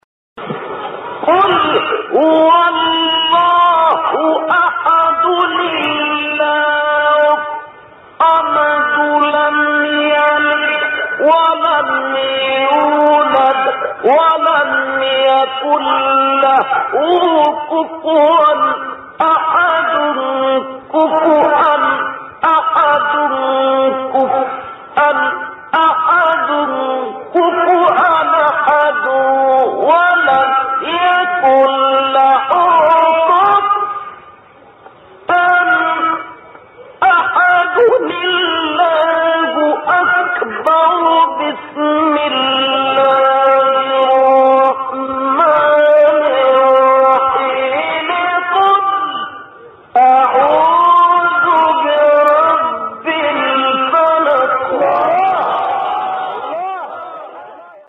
مقام : رست اختلاف قرائت : حفص * ابن کثیر – کسایی * ورش * حمزه